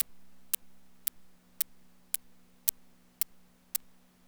Especially the hats when the “grain” is turned all the way down.
That short hihat sound you’ve posted isn’t very informative, since it’s … very short …